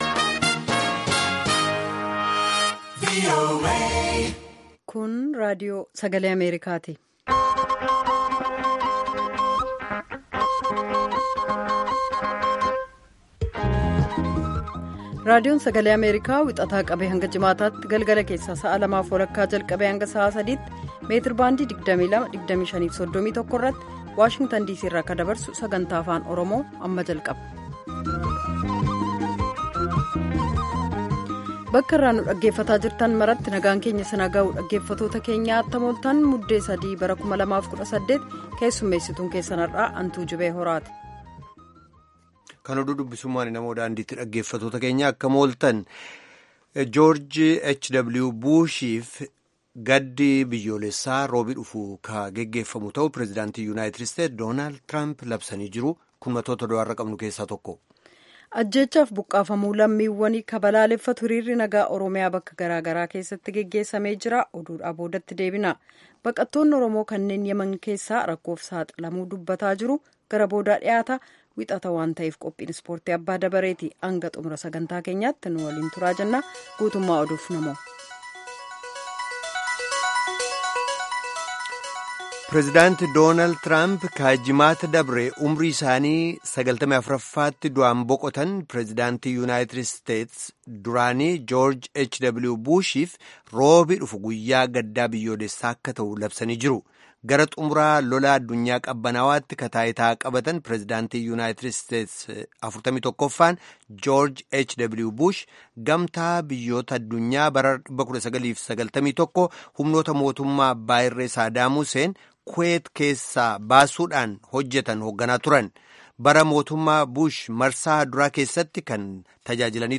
Half-hour broadcasts in Afaan Oromoo of news, interviews with newsmakers, features about culture, health, youth, politics, agriculture, development and sports on Monday through Friday evenings at 8:30 in Ethiopia and Eritrea.